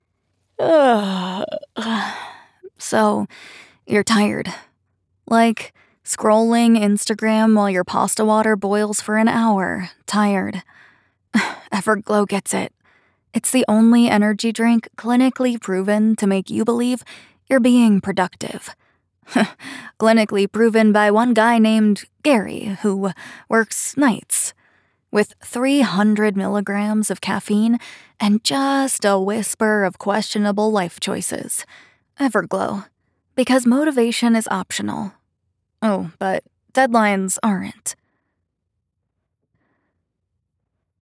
Commercial // Dry, Sarcastic, Funny